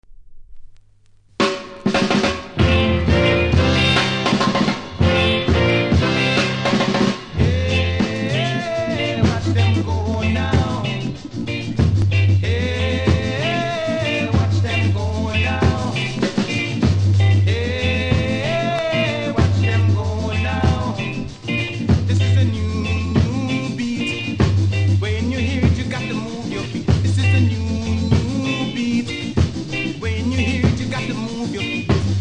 多少うすキズありますが音は良好なので試聴で確認下さい。